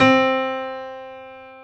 C3-PNO93L -L.wav